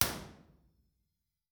mine_site2_2way_mono_processed.wav